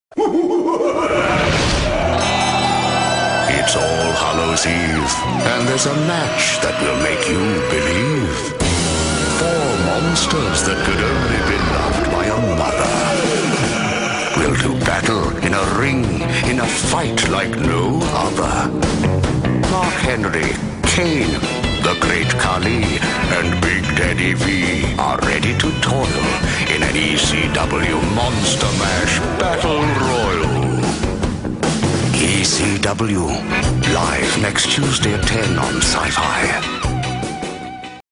goofy commercials with the world’s worst Boris Karloff impersonator!
monstermashpromo.mp3